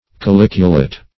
Caliculate \Ca*lic"u*late\, a.